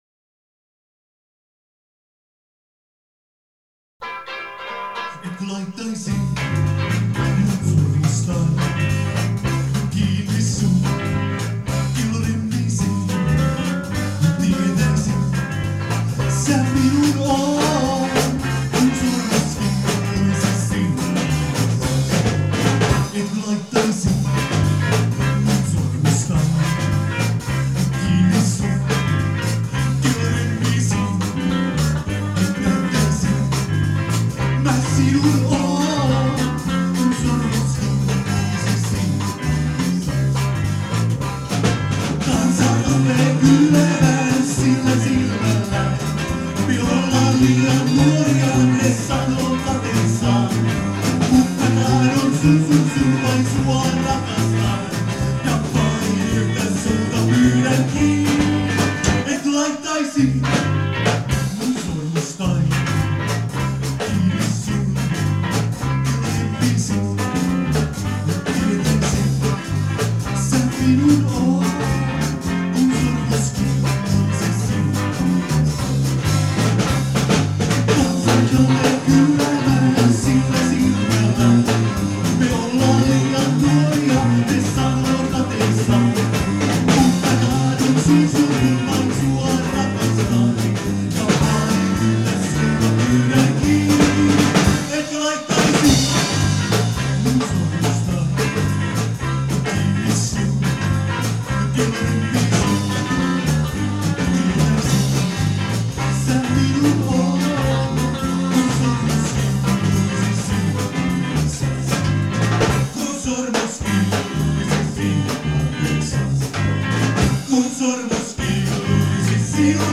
Äänitetty treenikämpällä 2003